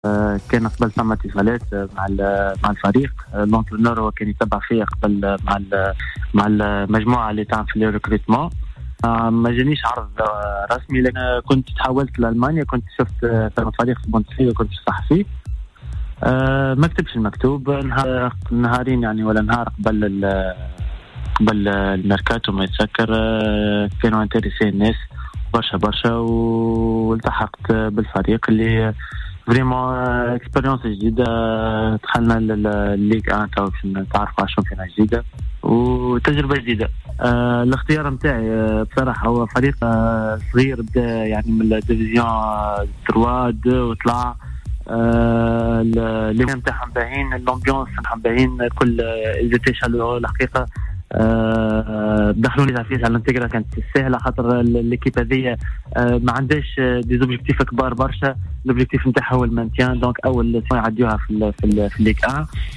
تحدث اللاعب التونسي امين الشرميطي خلال مداخلة في برنامج cartes sur table عن انتقاله الي فريق اجاكسيو الفرنسي بعد ان فسخ عقده مع فريقه السابق زويرخ السويسري.